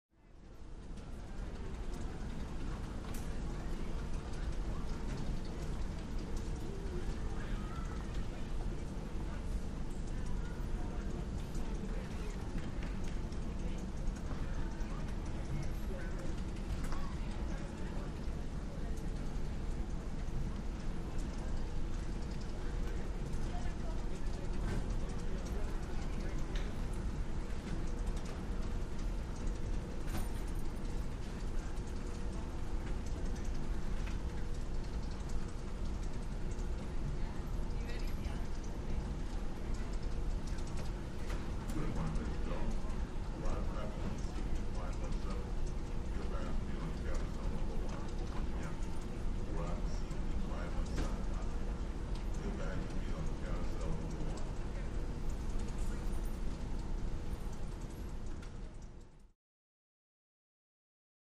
Baggage Claim Area, Quiet Motor Hum, Light Walla, Medium Point of View.